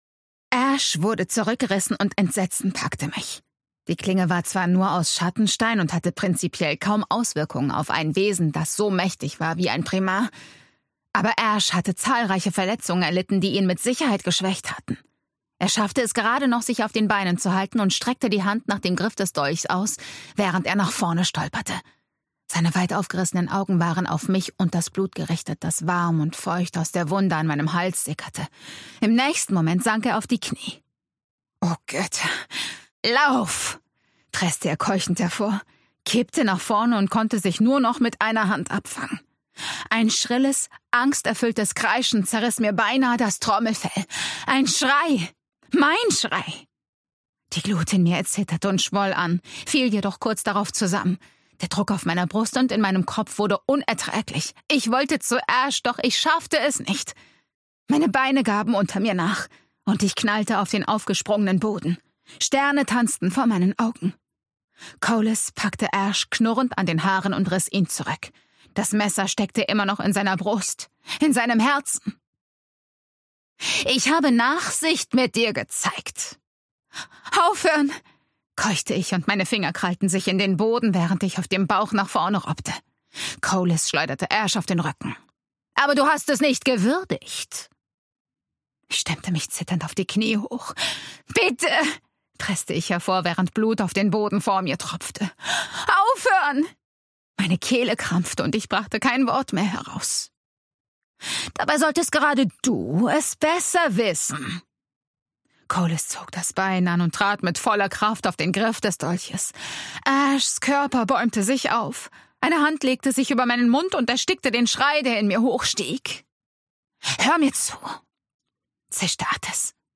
Ausgabe: Ungekürzte Lesung